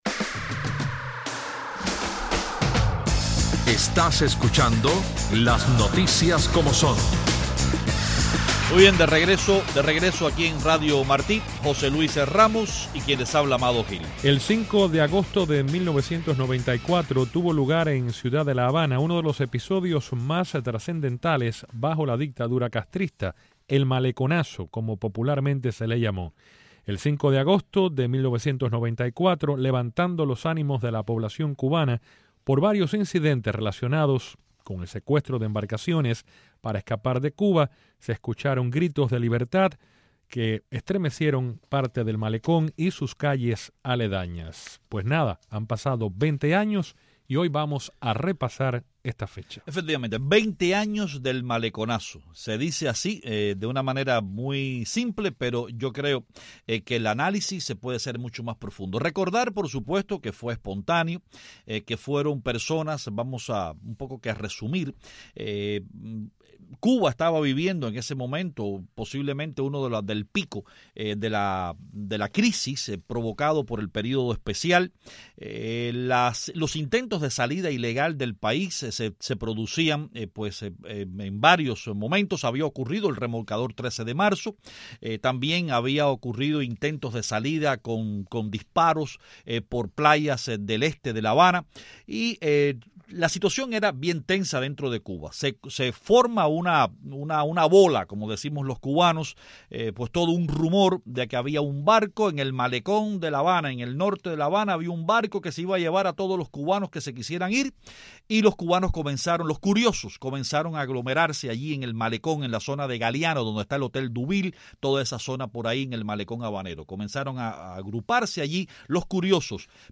Este martes, 5 de agosto, se cumplen 20 años del llamado Maleconazo, una protesta callejera espontánea y sin precedentes en La Habana donde se escucharon gritos de “abajo la dictadura” y “libertad”. Hoy en el programa tres cubanos narran lo que significó ese histórico día.